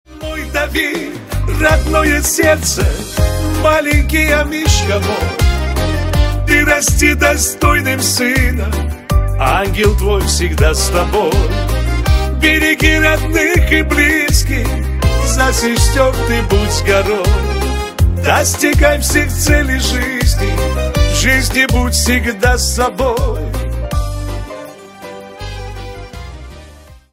шансон
душевные